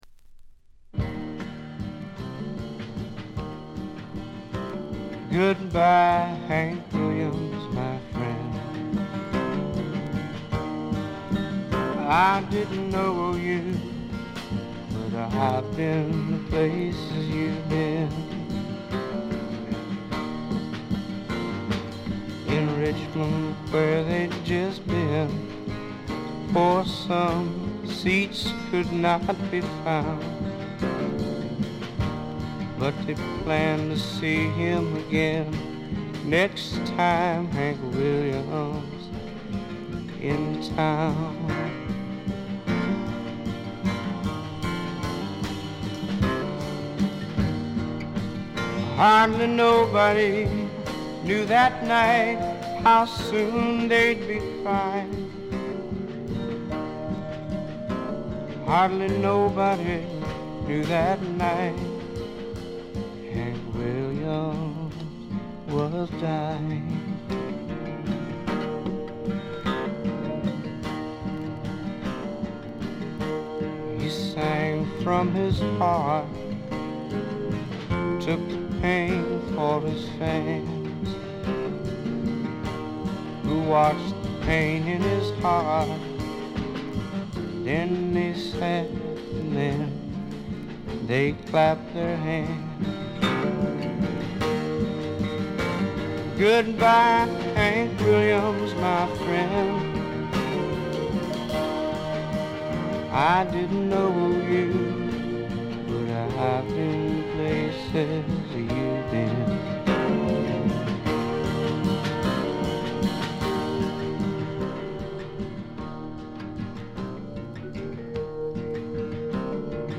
バックグラウンドノイズ、チリプチ多め大きめ。
フォーク、ロック、ジャズ等を絶妙にブレンドした革新的ないでたちでの登場でした。
試聴曲は現品からの取り込み音源です。